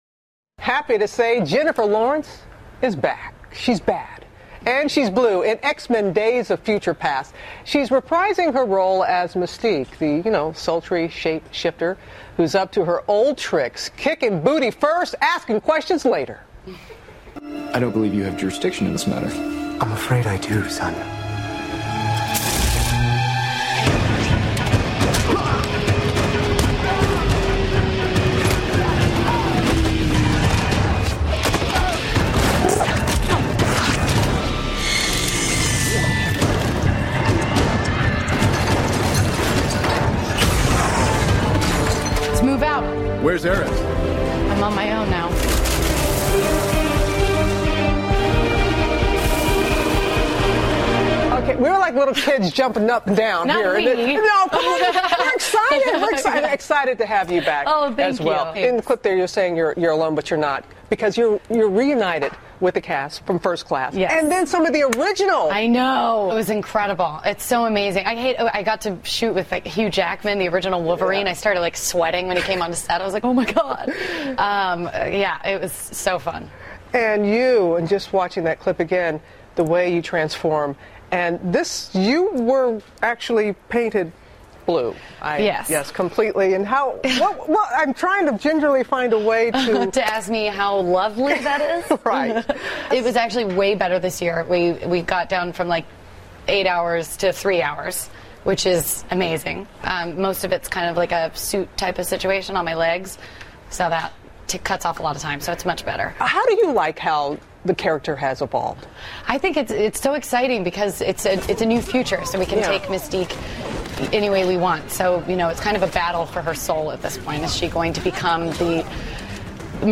访谈录 2014-05-29&05-31 詹妮弗·劳伦斯揭秘《x战警》幕后 听力文件下载—在线英语听力室